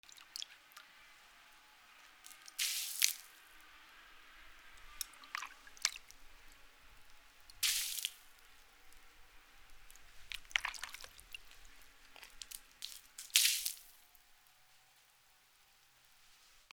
/ M｜他分類 / L30 ｜水音-その他
水を撒く